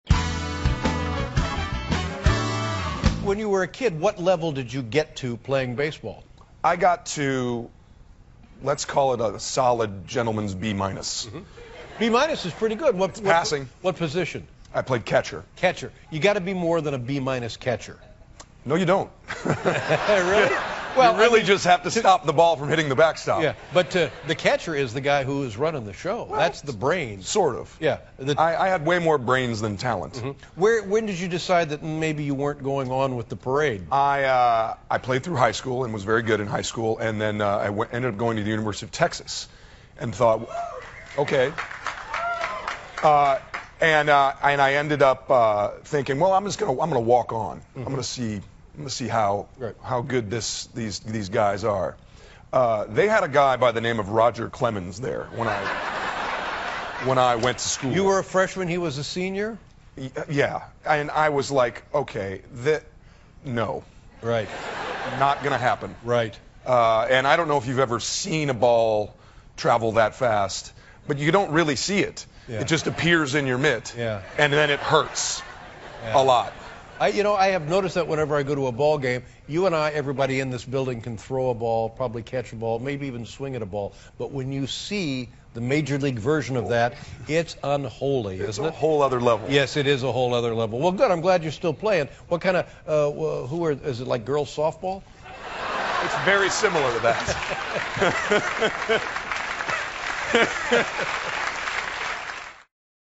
访谈录 2012-03-04&03-06 《广告狂人》主演乔·汉姆专访 听力文件下载—在线英语听力室